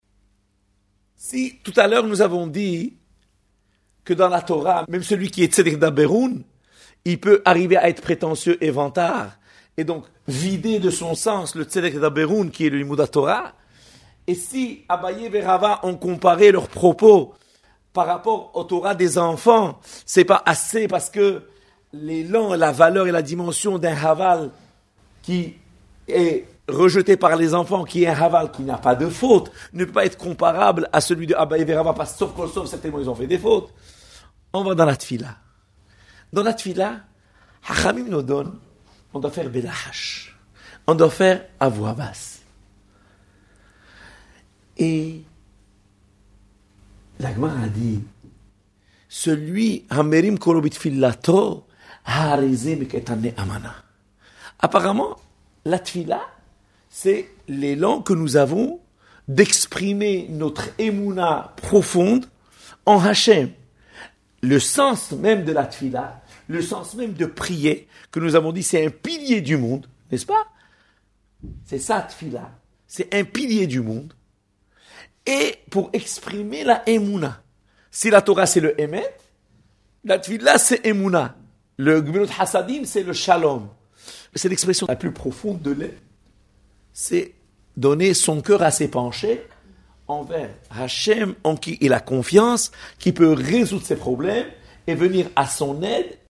Exposé magistral